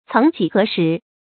céng jǐ hé shí
曾几何时发音
成语正音 曾，不能读作“zēnɡ”。